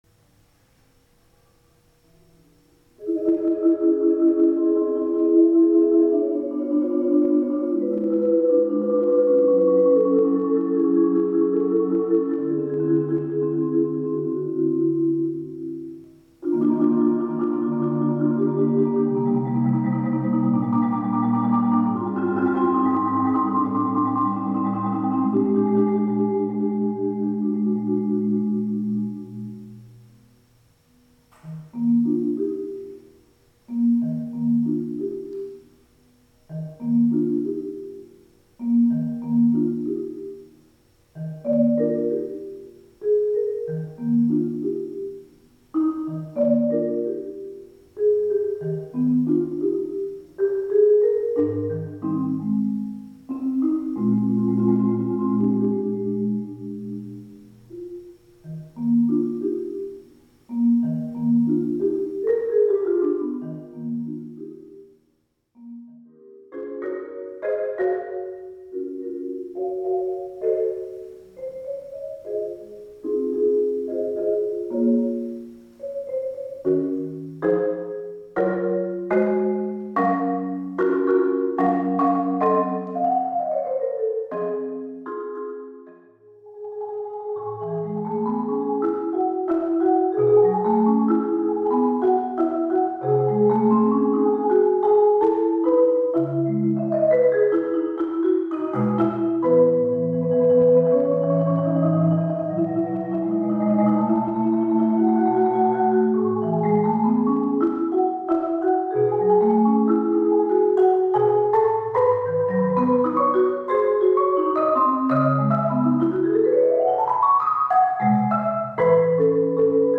楽譜 Marimba > Solo > C16-C76 (5oct.)
Marimba C16-C76 (5oct.)